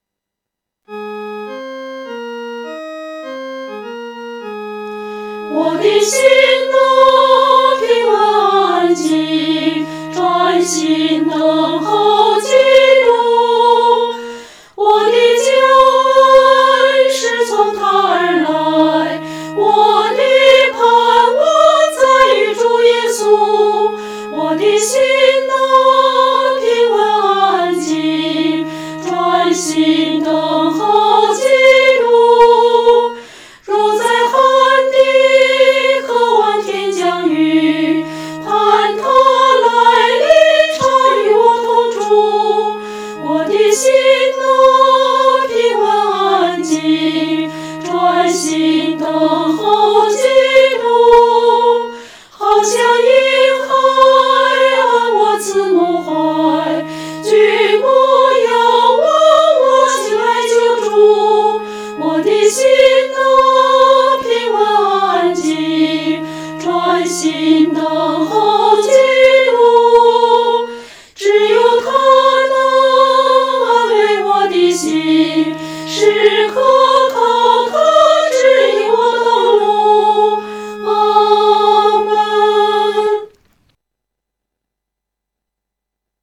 合唱
四声 下载
诗班在二次创作这首诗歌时，要清楚这首诗歌音乐表情是平静、柔和地。